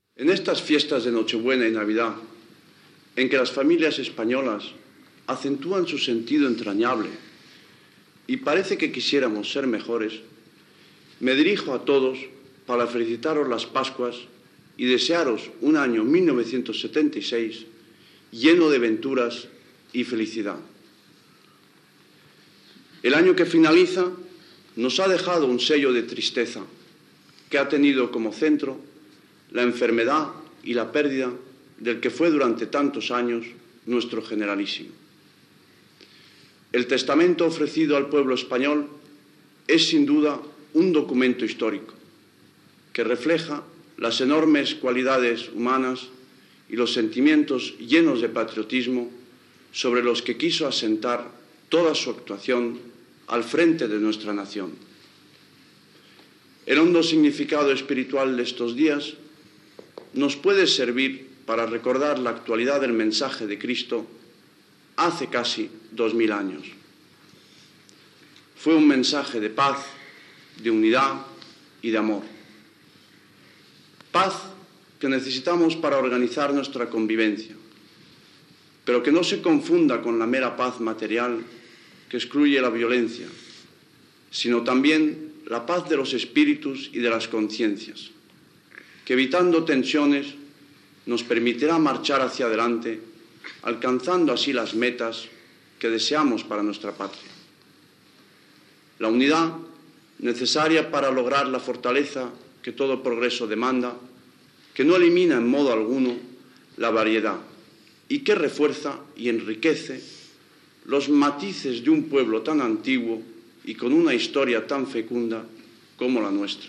Primer missatge de Nadal del rei Juan Carlos I: desig de bon any 1976, record a la figura del general Francisco Franco, mort aquell any, i esment al seu testament polític
Extret del programa "El sonido de la historia", emès per Radio 5 Todo Noticias el 29 de diciembre de 2012